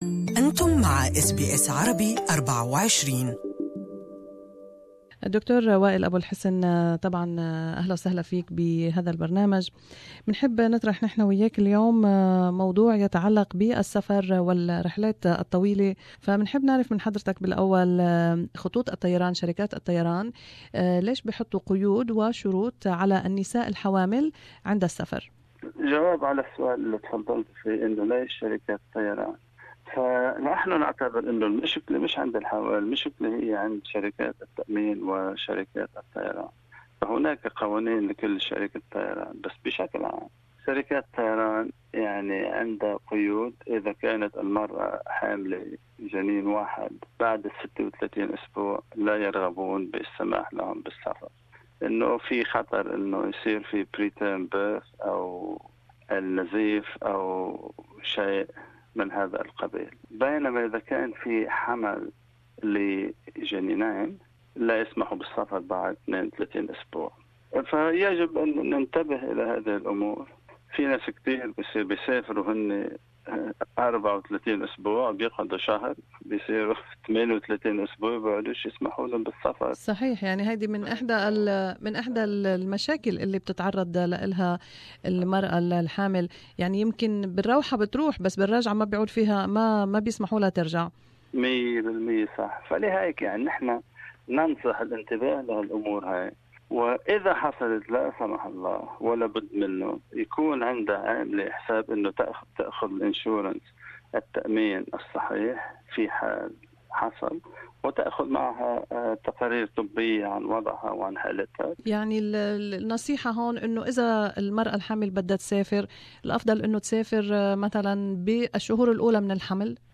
في هذا اللقاء